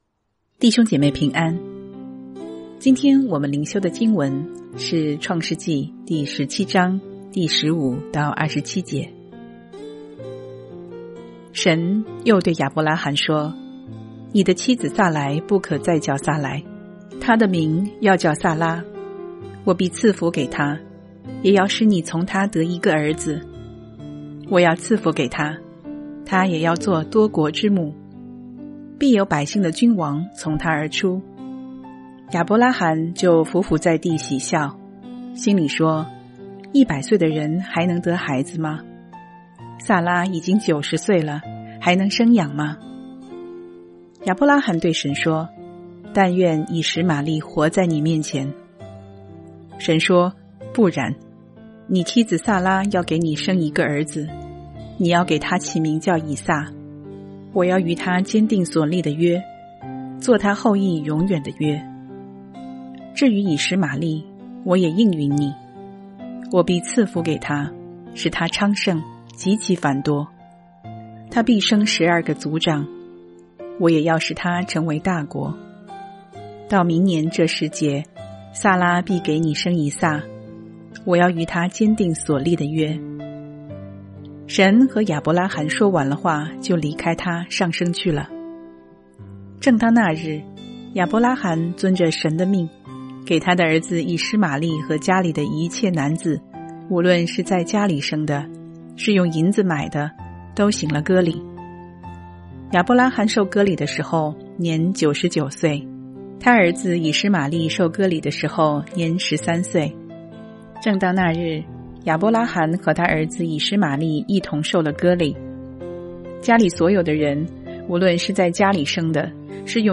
每天閱讀一段經文，聆聽牧者的靈修分享，您自己也思考和默想，神藉著今天的經文對我說什麼，並且用禱告來回應當天的經文和信息。